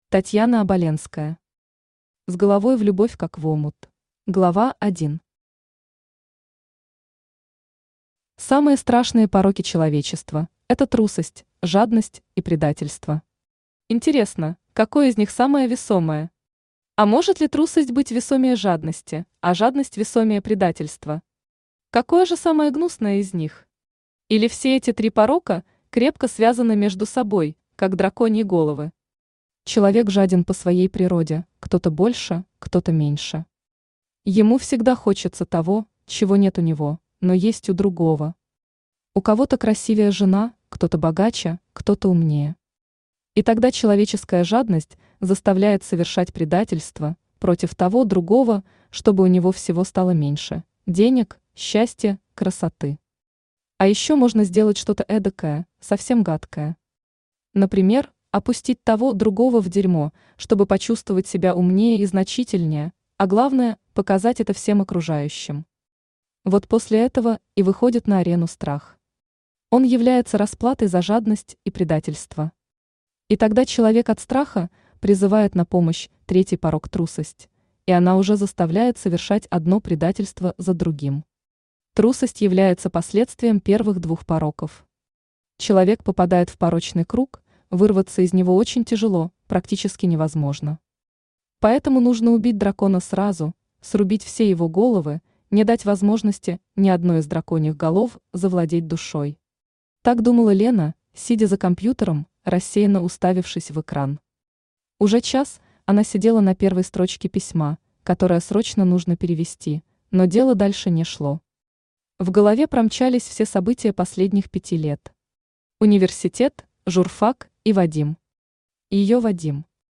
Aудиокнига С головой в любовь как в омут Автор Татьяна Оболенская Читает аудиокнигу Авточтец ЛитРес.